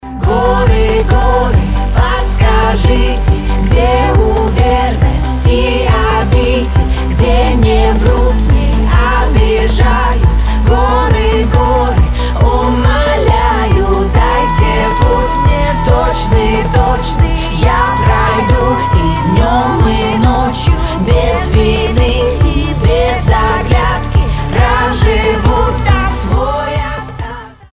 • Качество: 320, Stereo
поп
женский вокал
Ethnic